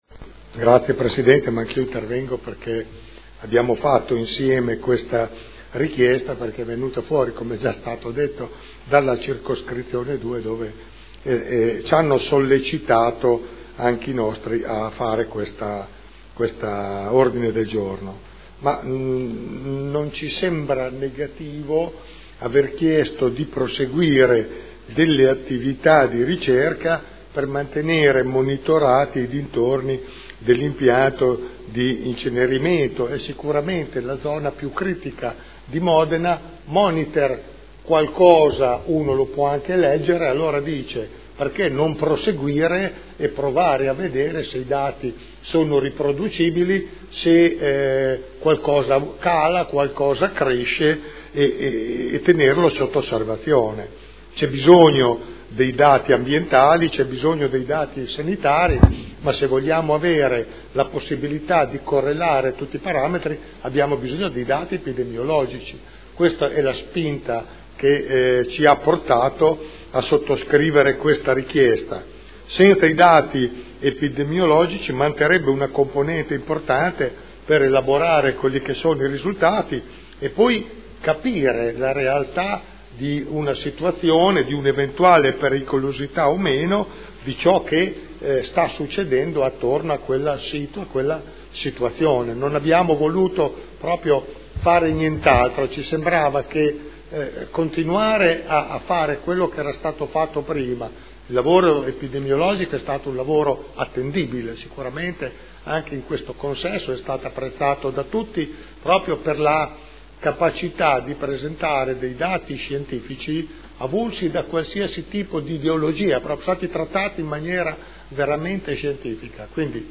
Gian Carlo Pellacani — Sito Audio Consiglio Comunale